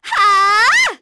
Artemia-Vox_Skill4.wav